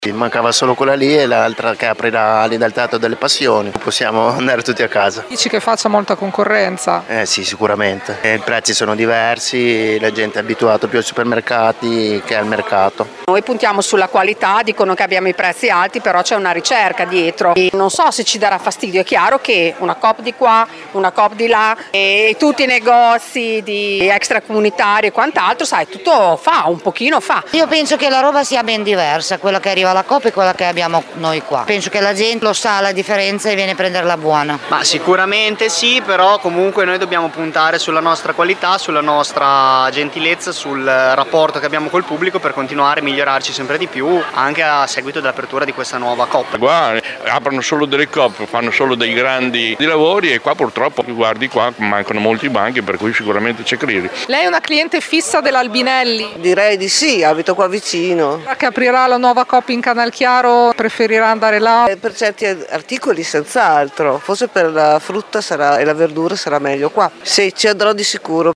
Preoccupati gli operatori del mercato Albinelli per la possibile perdita di clienti
VOX-ALBINELLI.mp3